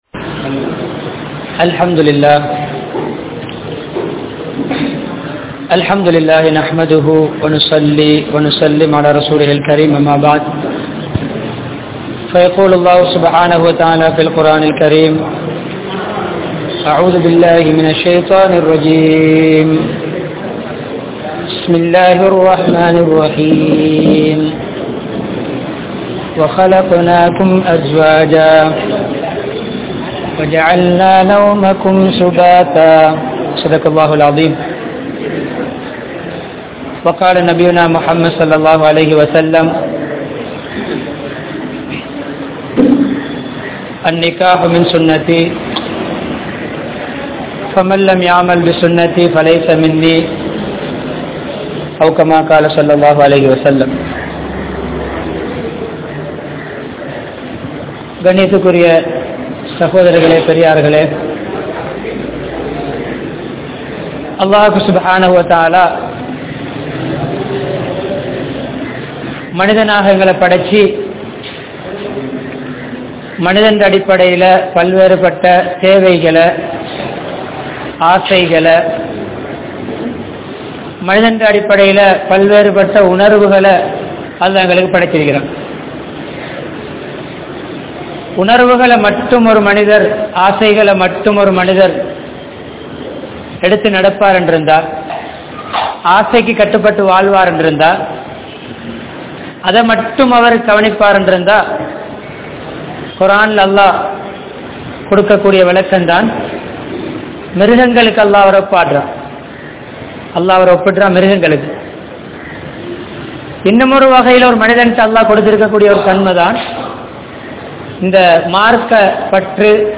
Mana Amaithiyai Eatpaduththum Vaalkai Murai (மன அமைதியை ஏற்படுத்தும் வாழ்க்கை முறை) | Audio Bayans | All Ceylon Muslim Youth Community | Addalaichenai